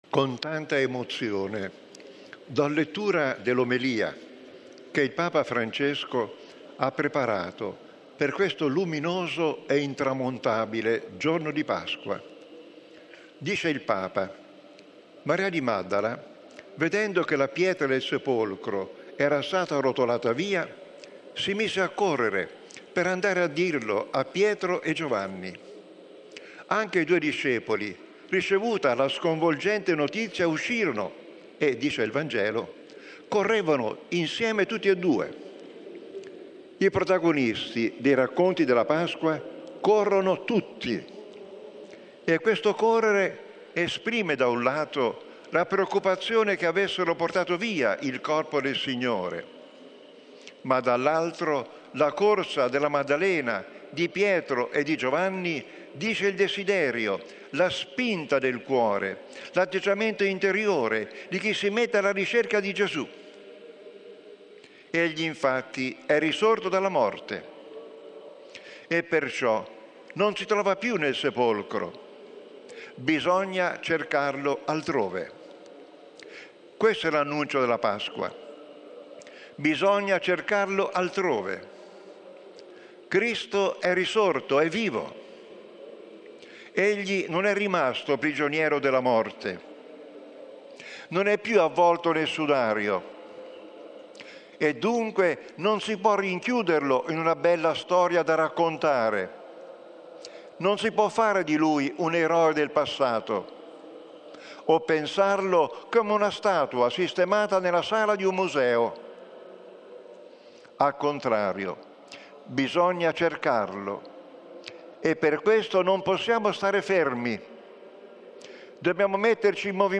This podcast offers the public speeches of the Holy Father, in their original languages.